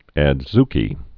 (ăd-zkē) or a·zu·ki bean (ə-z-)